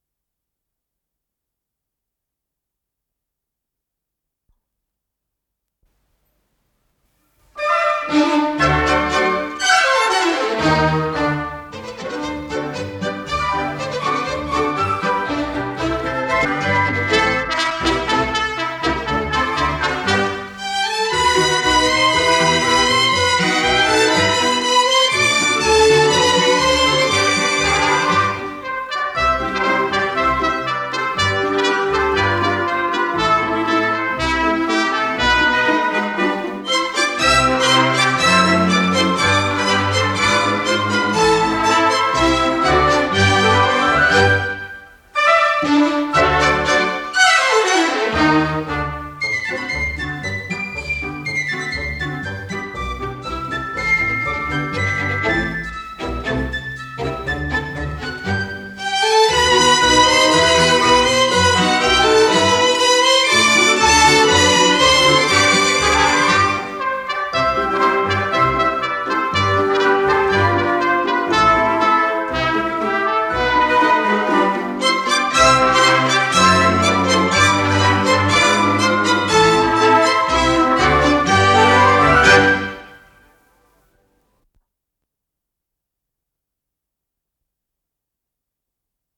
с профессиональной магнитной ленты
ПодзаголовокОркестровая заставка
Скорость ленты38 см/с